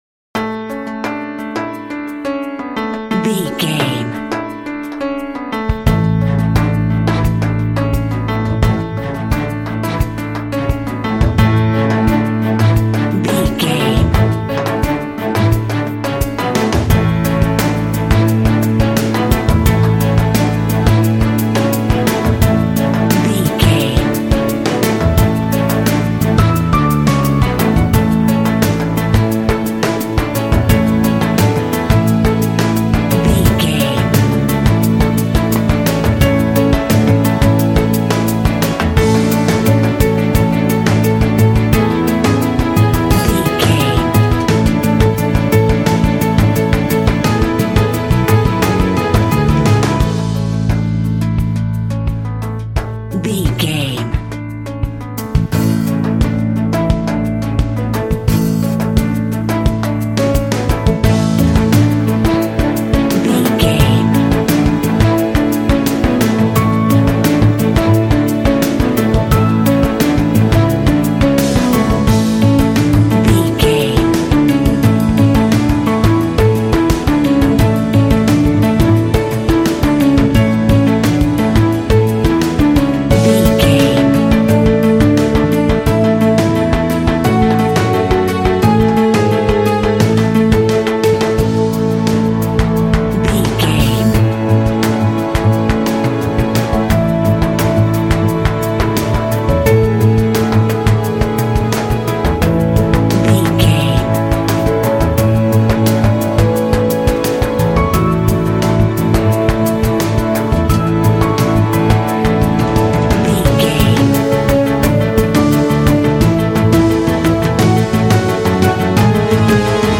Uplifting
Ionian/Major
soothing
cinematic
contemporary underscore